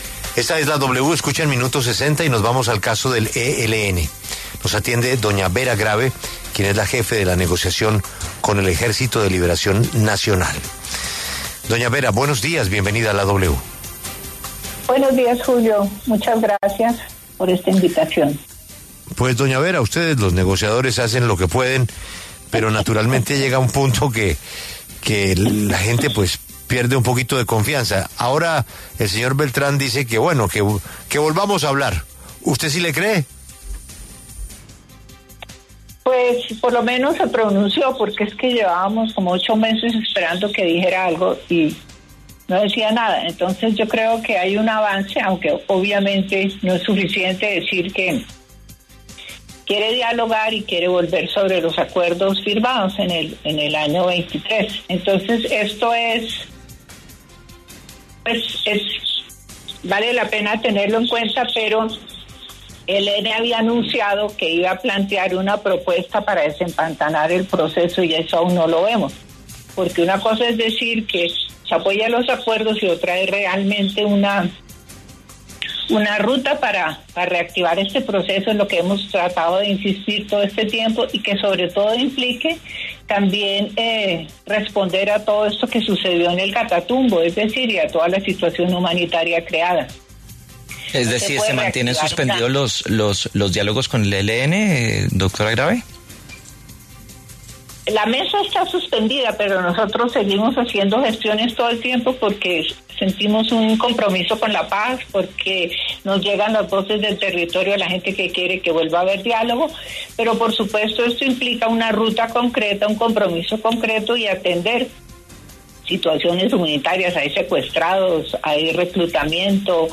Vera Grabe, jefe negociadora del Gobierno con el ELN, pasó por los micrófonos de La W, con Julio Sánchez Cristo para hablar sobre las declaraciones de ‘Pablo Beltrán’, cabecilla del ELN, en las que anunció que esa guerrilla quiere retomar los diálogos de paz.